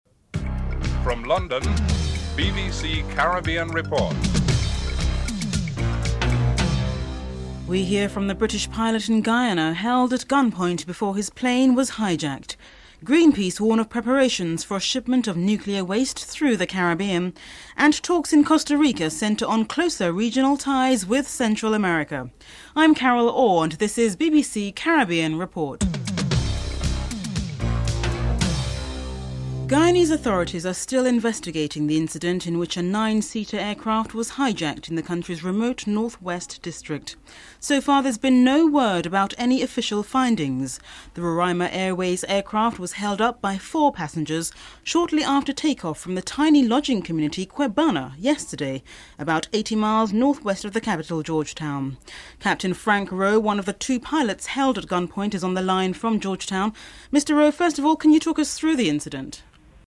1. Headlines (00:00-00:30)
Foreign Minister Ralph Maraj is interviewed (11:13-12:41)